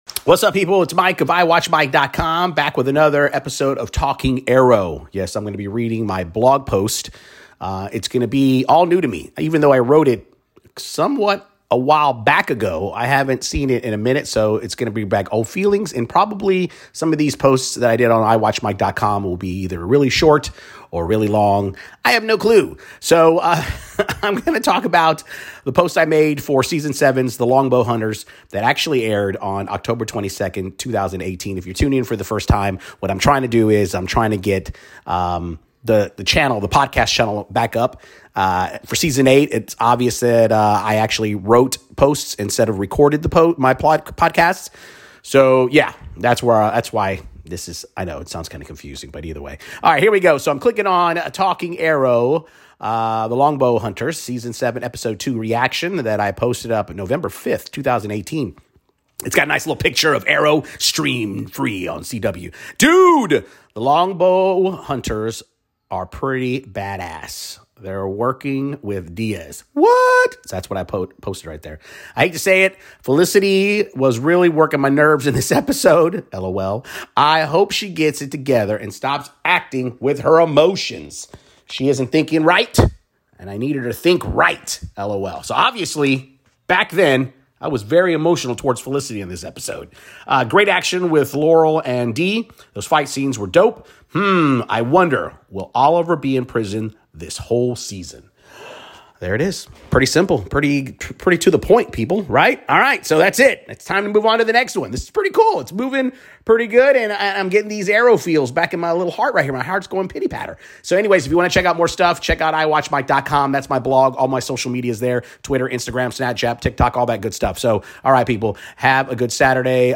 In this podcast, he reads to the Arrow fans.